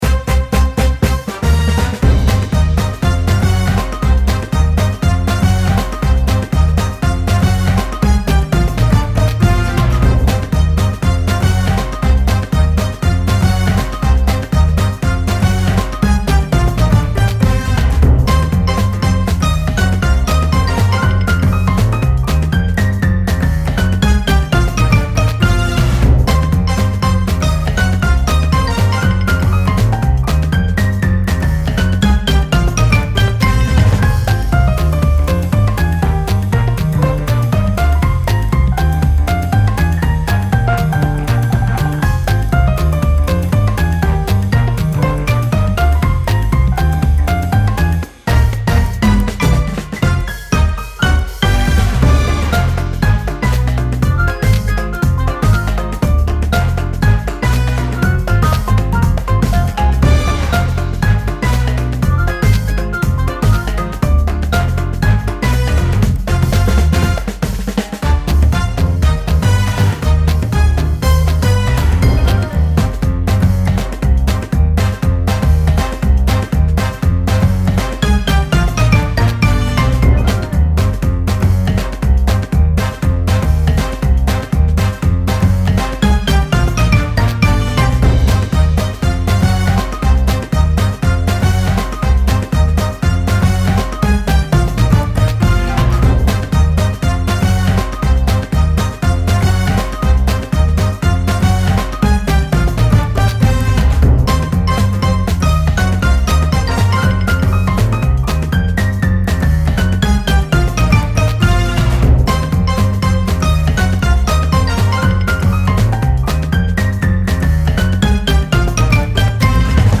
ビッグバンドっぽい明るくてジャズ感のあるアップテンポBGMです。 ノリノリな雰囲気です。